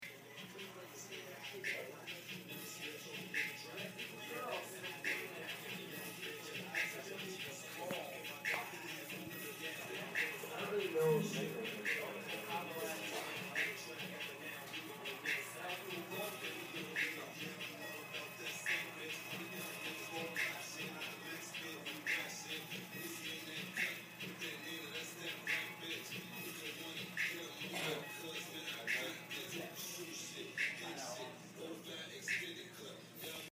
Field Recording #3
Walking down the hallway on my floor at night.
Sounds Heard: Rap music, and a conversation between two men.